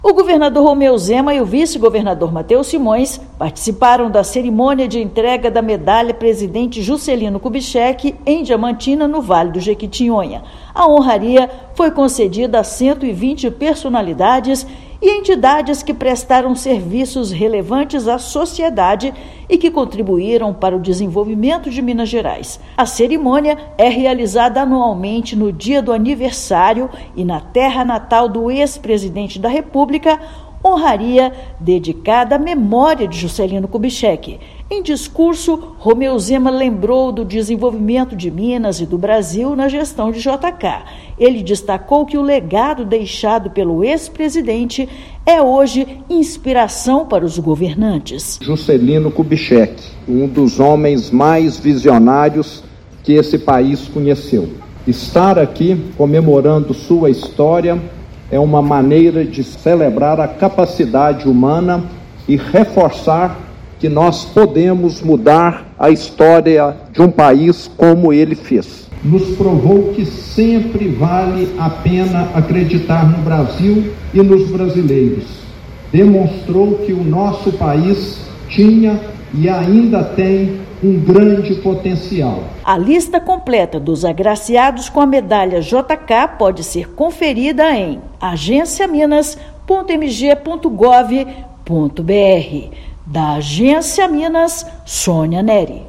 Governador e vice-governador presidiram a solenidade que homenageou 120 personalidades com a honraria que leva o nome do ex-presidente do Brasil e filho ilustre da cidade. Ouça matéria de rádio.